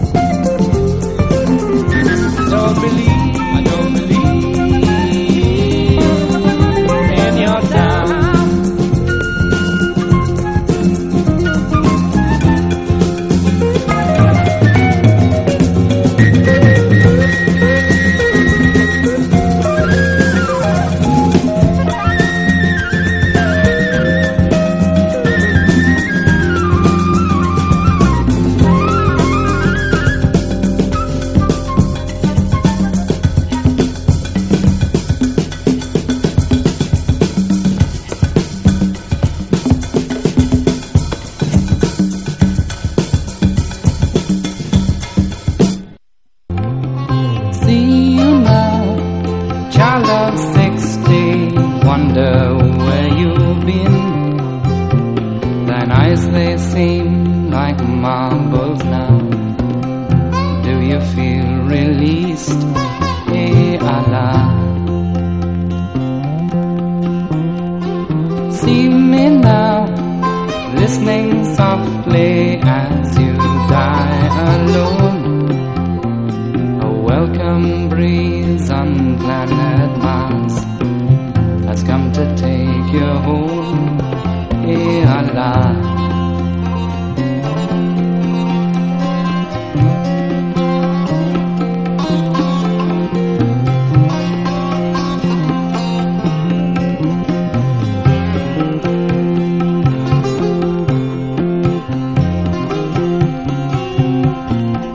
ROCK / S.S.W./A.O.R. / FOLK / BLUE GRASS / CELTIC / COUNTRY
85年ローカル・フォーク・フェスティヴァル
の実況録音盤。